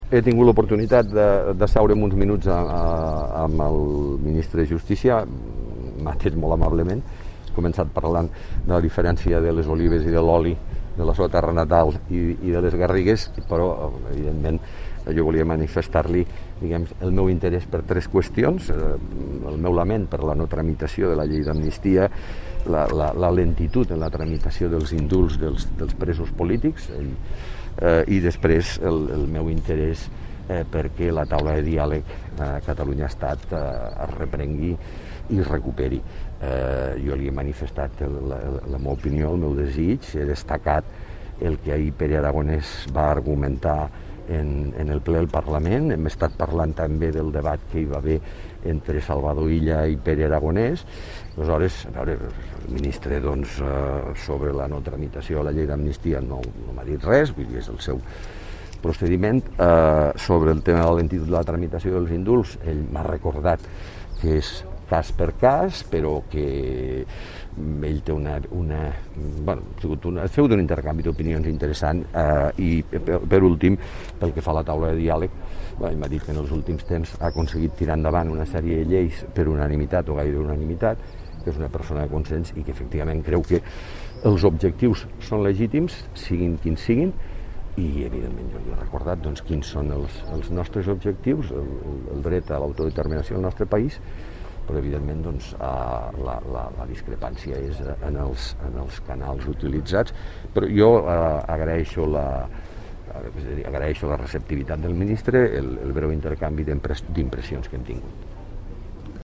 tall-de-veu-miquel-pueyo